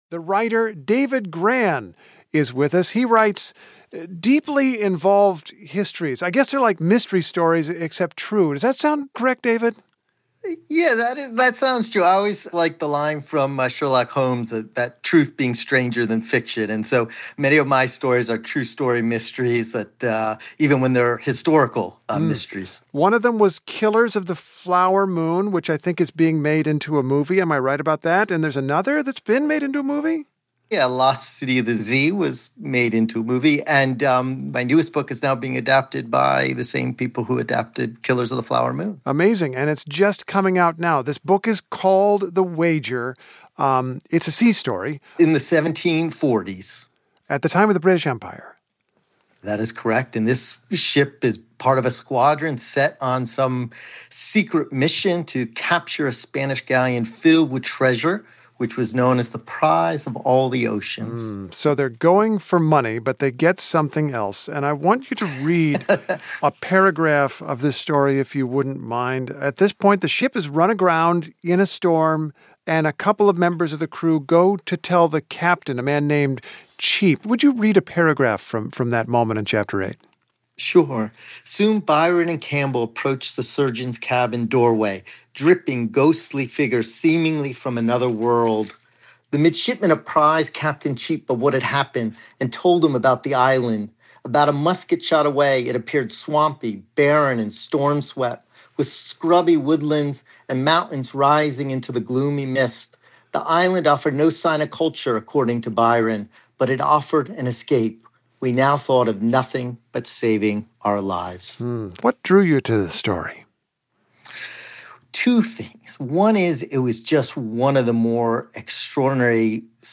Steve Inskeep talks to David Grann about his retelling of the dramatic Wager Mutiny of 1741.